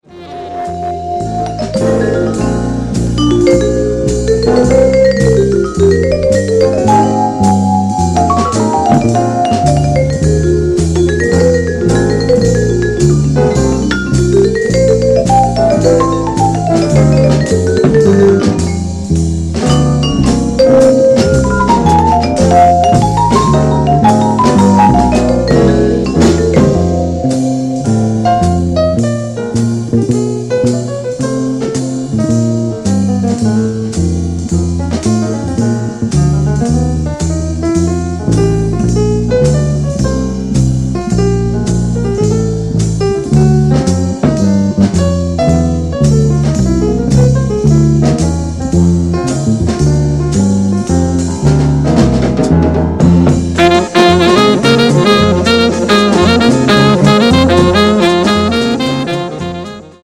in a Zurich studio